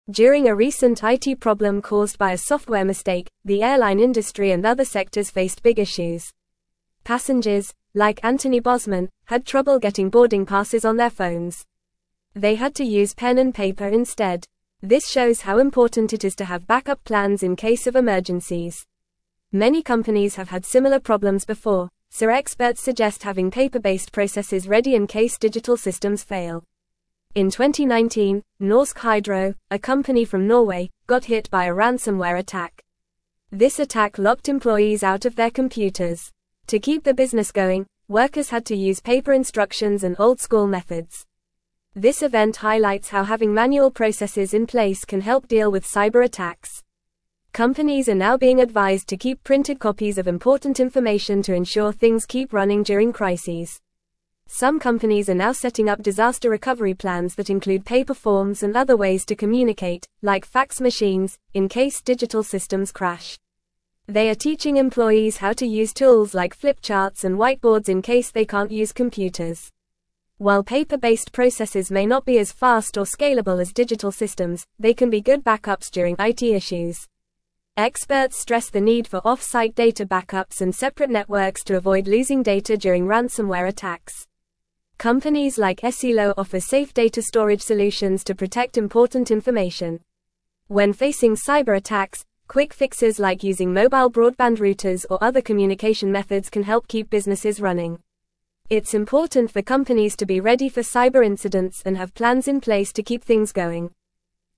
Fast
English-Newsroom-Upper-Intermediate-FAST-Reading-The-Importance-of-Manual-Processes-During-IT-Failures.mp3